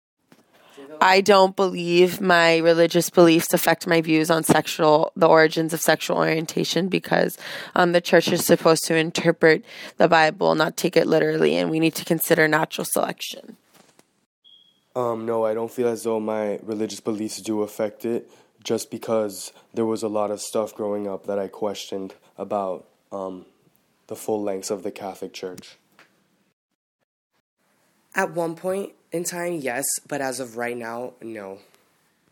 Street Interviews